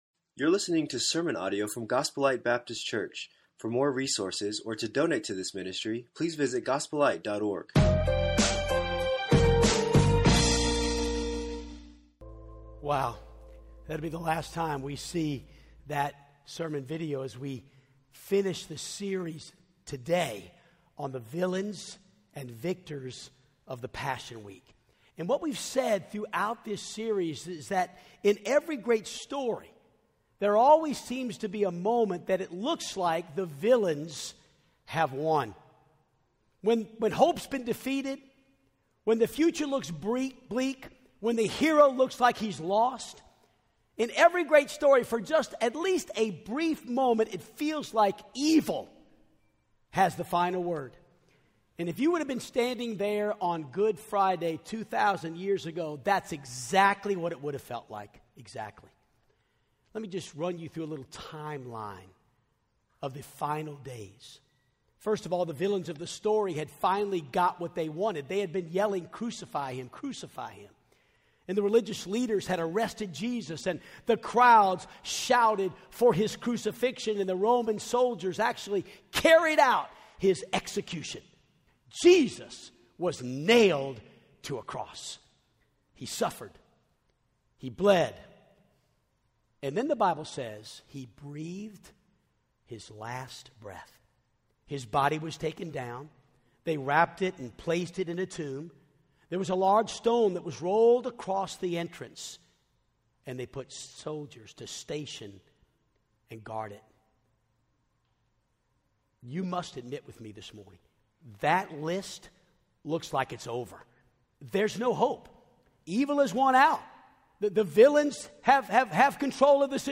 Villains and Victors - Sermon 3 - Easter Sunday